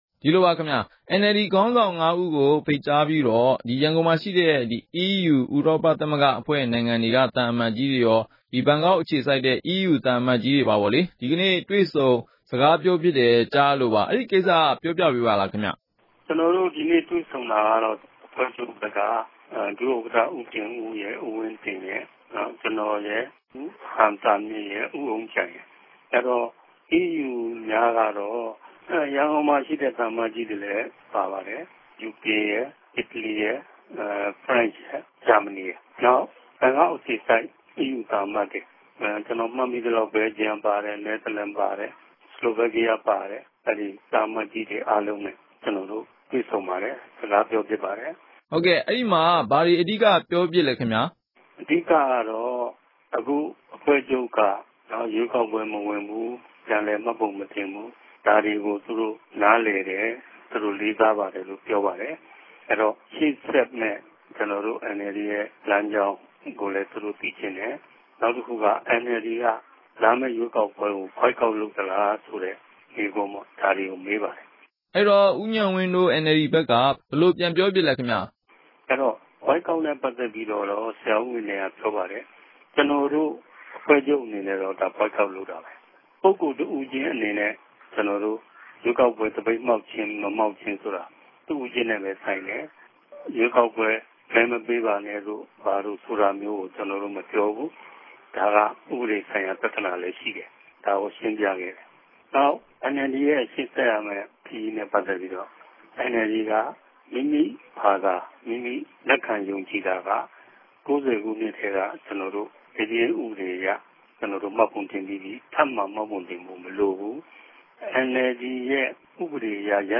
ဆက်သြယ် မေးူမန်းထားတာ နားဆငိံိုင်ပၝတယ်။